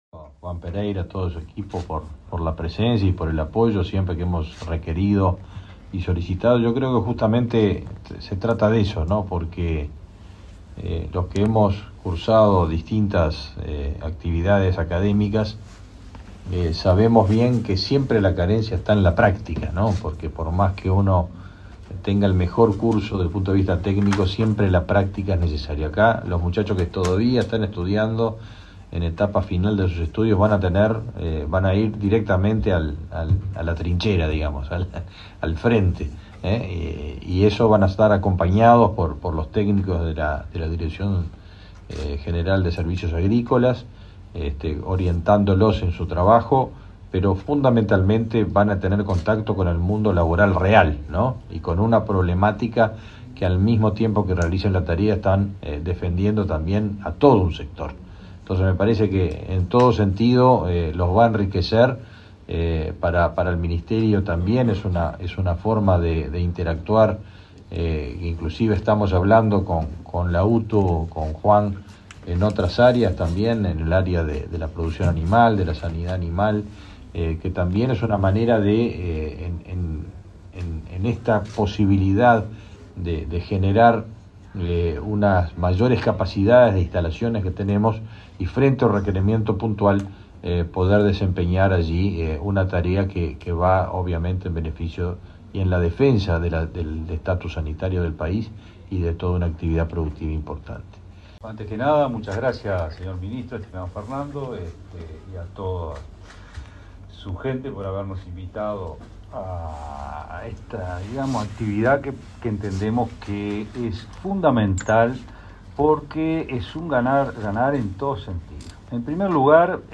Palabras de autoridades en acto en el MGAP